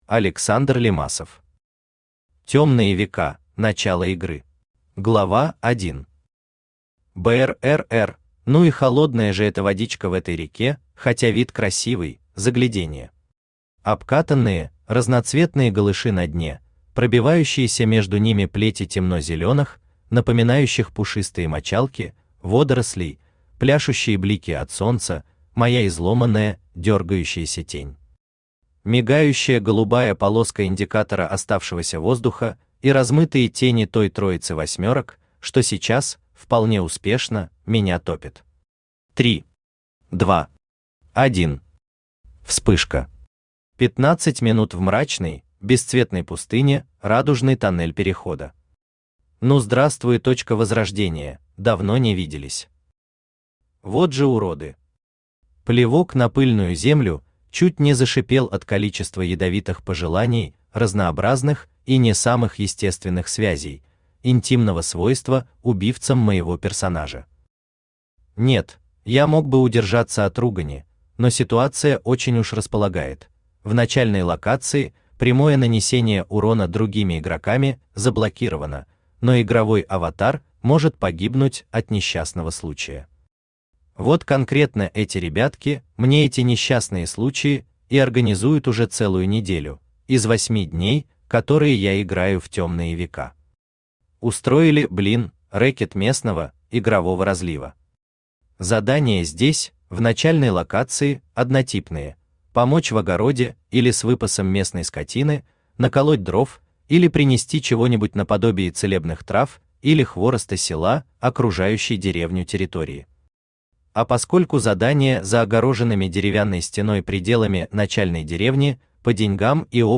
Аудиокнига Темные века: Начало игры | Библиотека аудиокниг
Aудиокнига Темные века: Начало игры Автор Александр Геннадьевич Лимасов Читает аудиокнигу Авточтец ЛитРес.